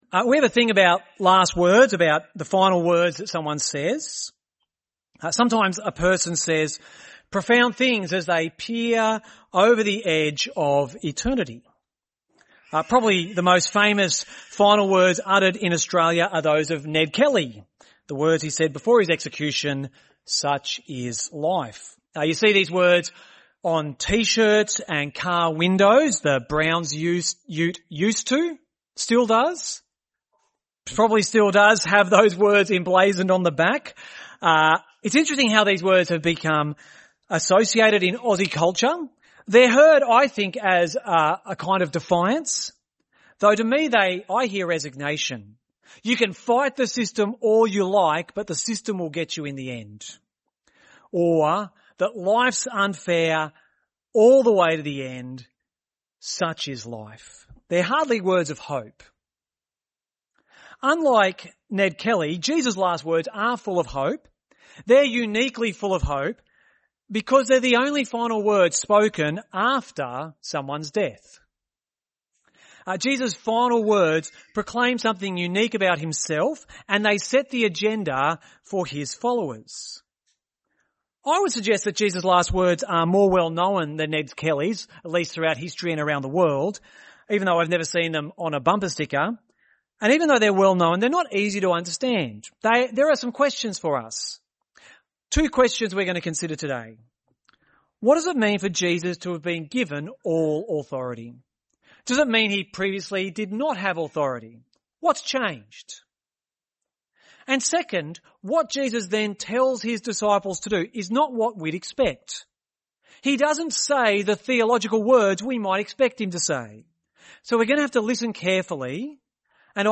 Gympie Presbyterian Church
In this Resurrection Sunday sermon we hear of Jesus complete (All) Authority, what it tells us about Jesus and what Jesus says to us.